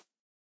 rabbit_hop3.ogg